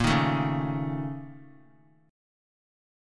BbmM7bb5 chord